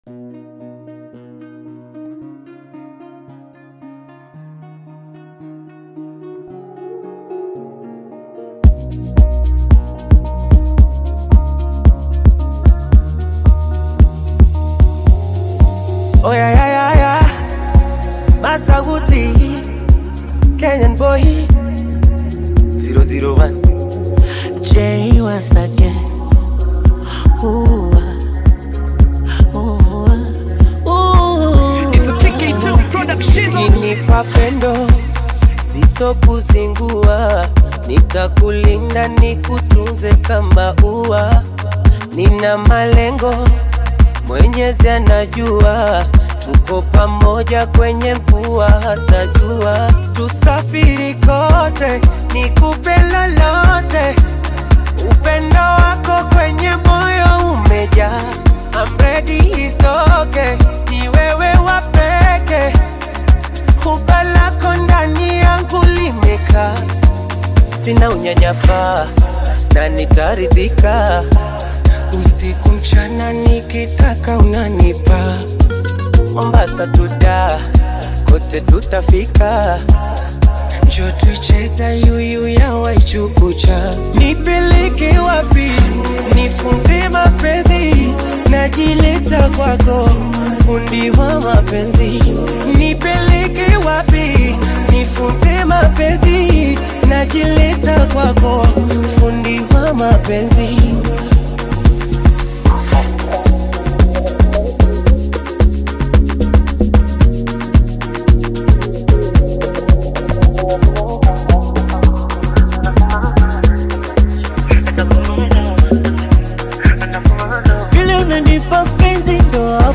catchy Afro-Pop/Bongo Flava single
blends vibrant Kenyan and Tanzanian vibes
Genre: Afrobeat